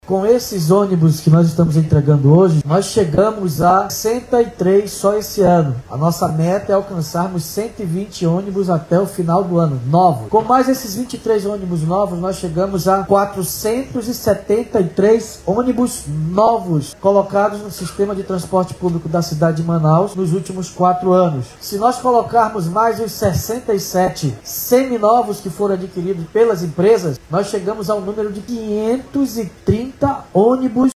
Os novos ônibus foram entregues nesta quinta-feira, 10 de abril, durante uma cerimônia no Terminal 7, zona Oeste de Manaus.
A meta é chegar a 120 veículos até o final de 2025, como explica o Prefeito David Almeida.